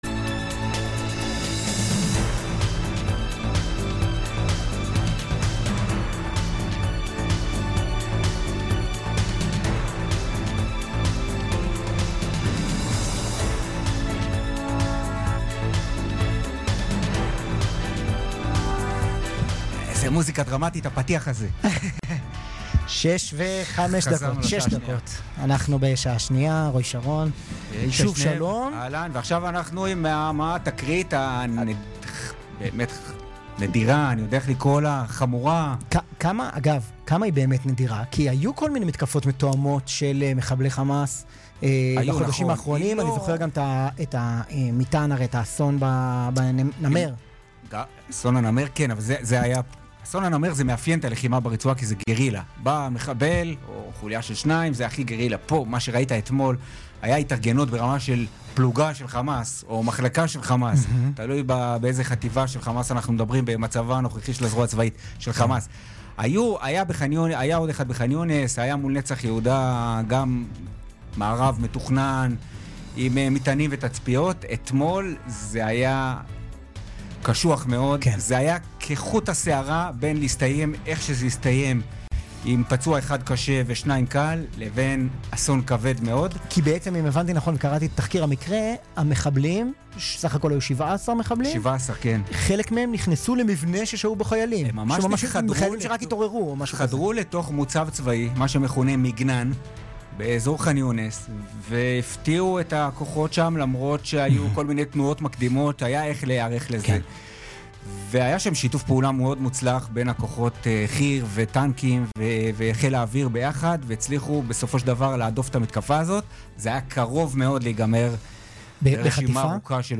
ראיונות עם מפקדים, אודות ההתקלות של לוחמי חטיבת כפיר בחאן יונס